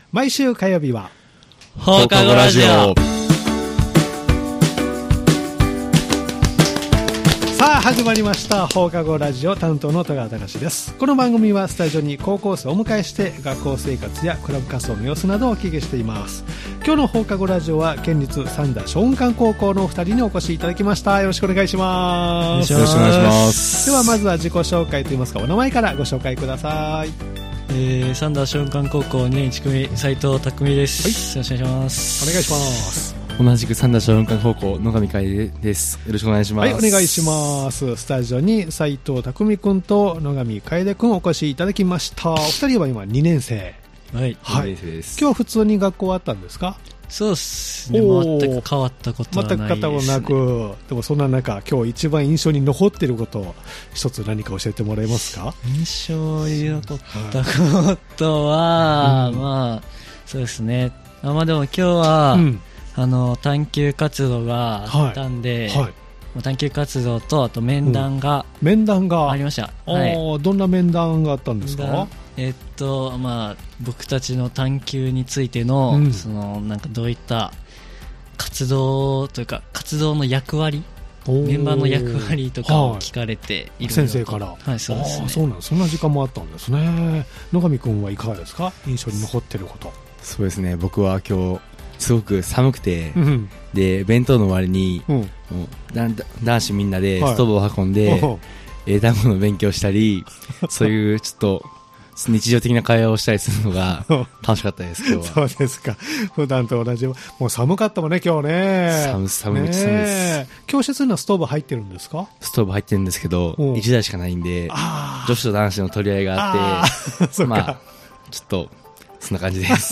毎回高校生の皆さんをスタジオにお迎えして、学校生活、部活、学校行事などインタビューしています（再生ボタン▶を押すと放送が始まります）